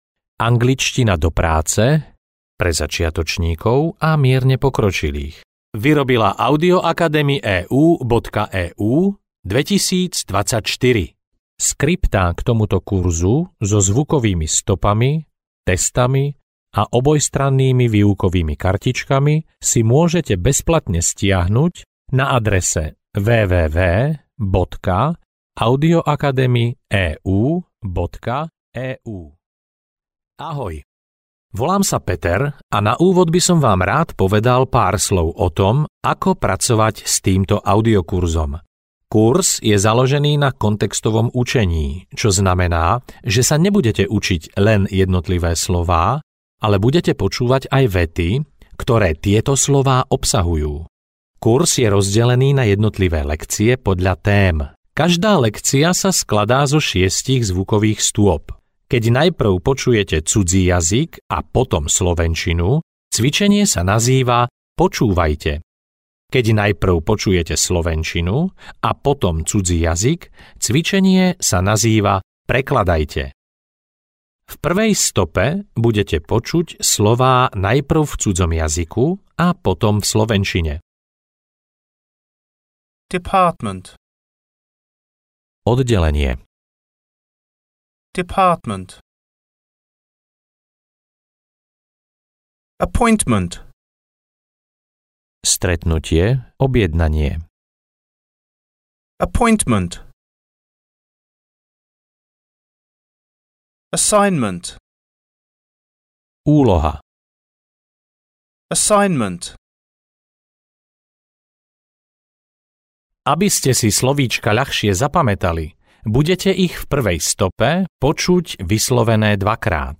Audio knihaAngličtina do práce A1-B1
Ukázka z knihy